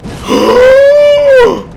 Gasp